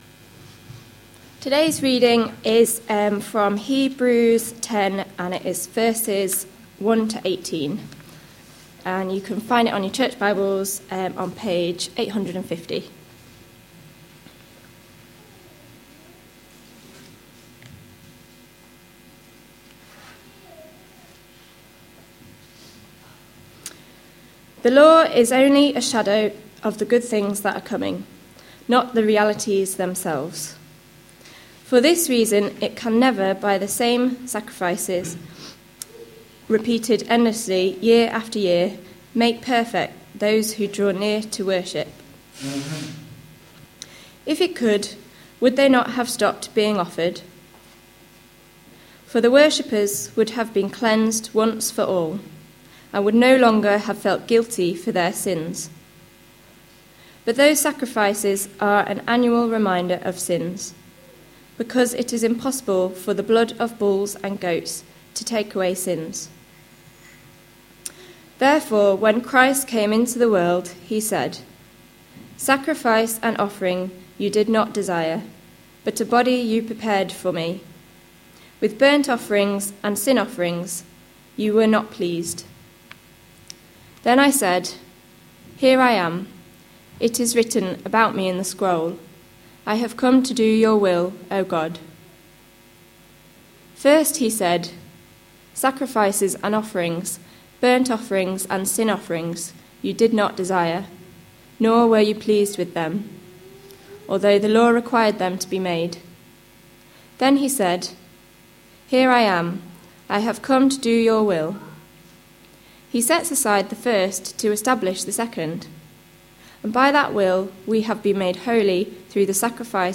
A sermon preached on 15th February, 2015, as part of our Hebrews series.